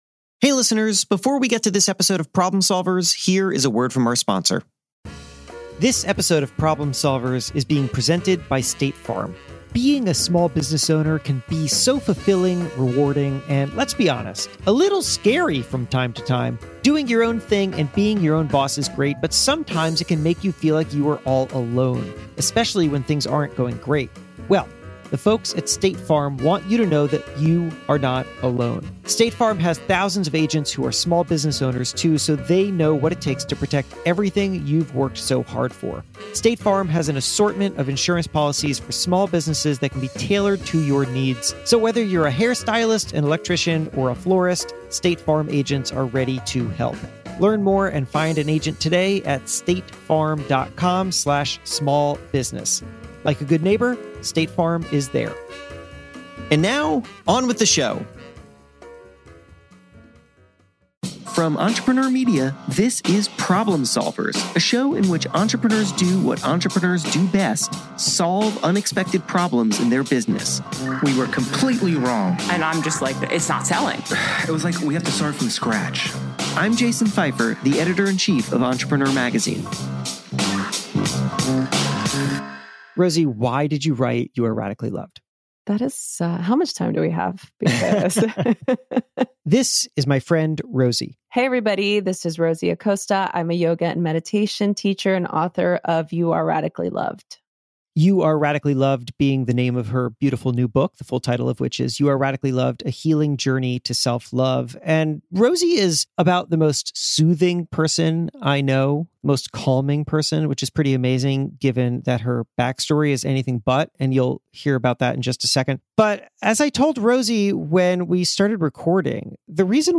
an inspiring conversation about taking care of yourself